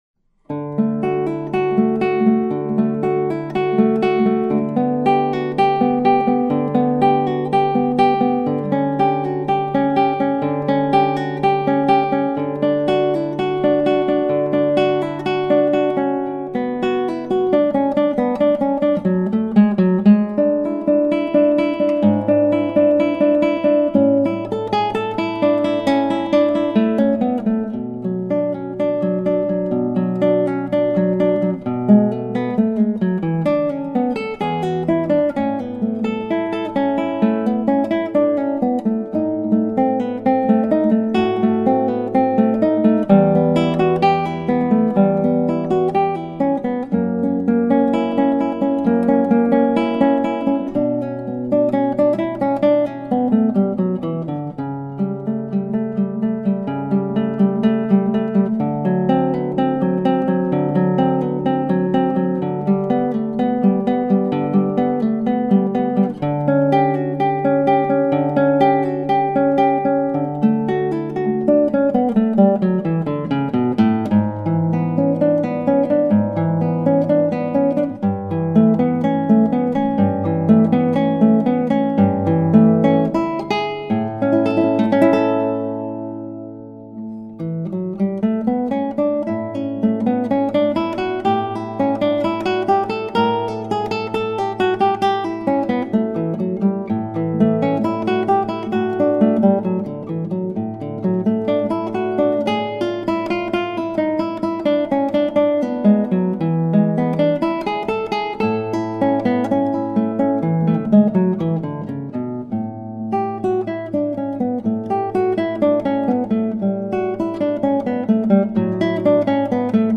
When you listen to a recording you are hearing many things - the player, the guitar, the recording equipment, and  the acoustics of the room....but you'll still get an idea of the guitar sound from the recordings.
Here are some older recordings done on a 2008 Dominelli lattice-braced concert classical guitar. The guitar has a cedar top and East Indian rosewood back and sides.